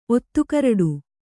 ♪ ottukaraḍu